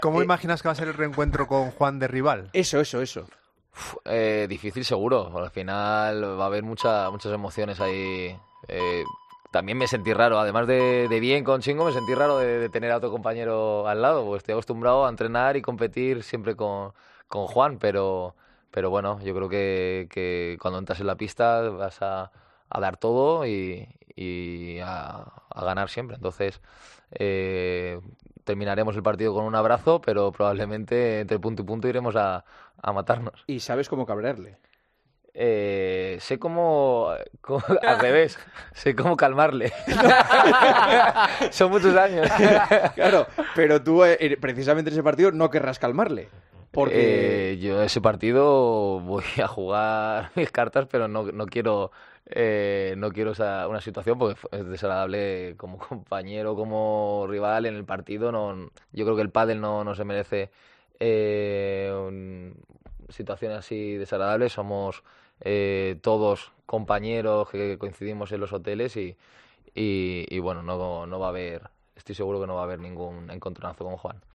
Después de romperse la mejor pareja del mundo de este deporte, cada una de las partes comienza un nuevo camino sobre el que habló con Juanma Castaño en El Partidazo de COPE
Ale Galán en el estudio de COPE y Juan Lebrón